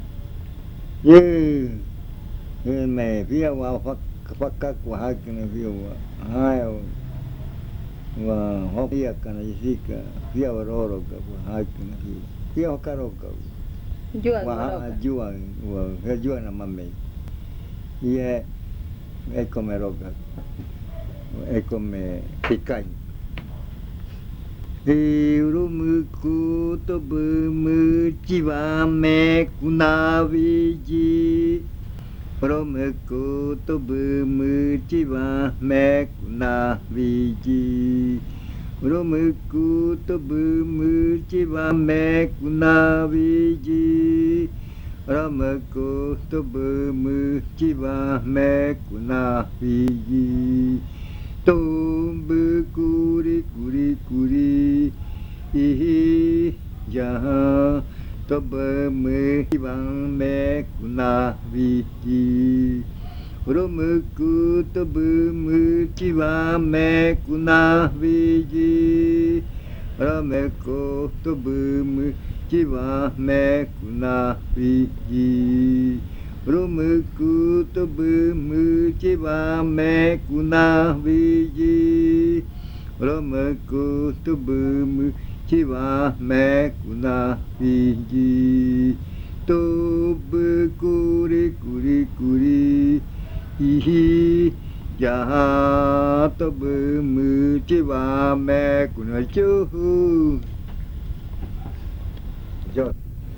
Leticia, Amazonas
Se oye una canción.
A chant is heard.
fruit ritual
Cantos de yuakɨ